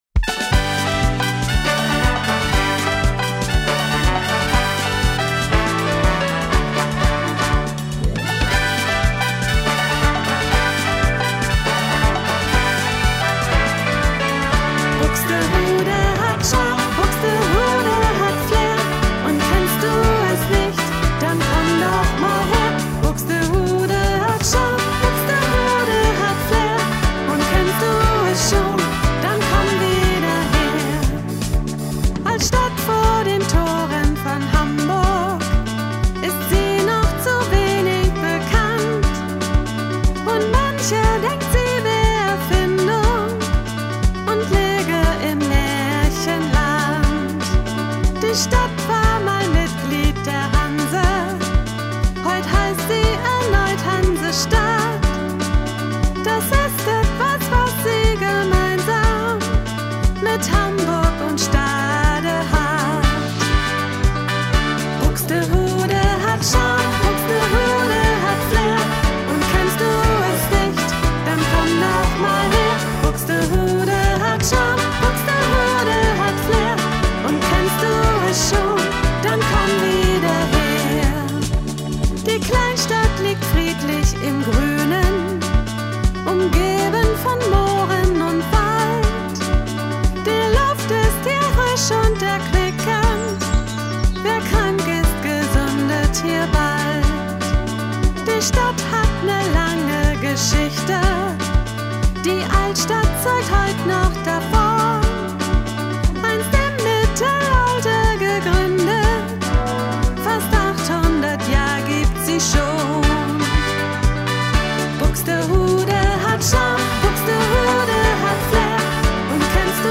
Wir haben die Lieder in Musikstudios in Hamburg und Buxtehude aufgenommen.